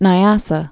(nī-ăsə, nyäsä), Lake also Lake Malawi